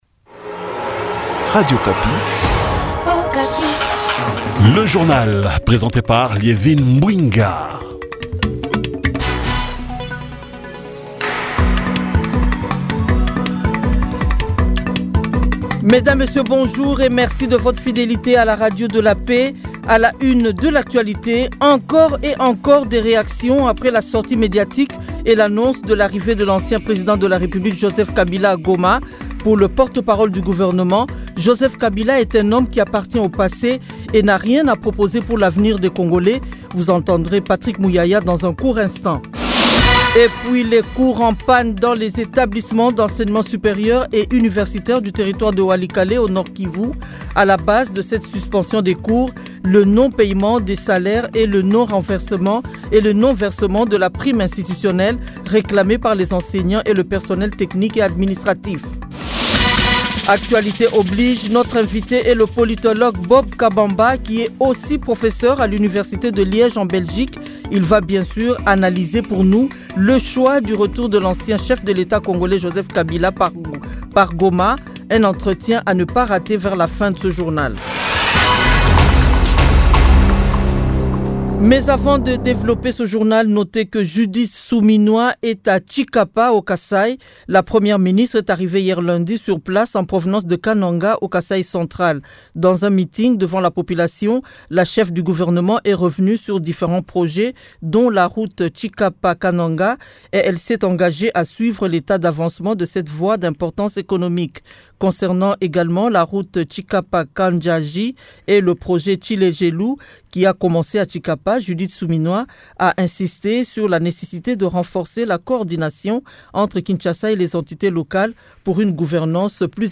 Journal 6h et 7h mardi 27 mai 2025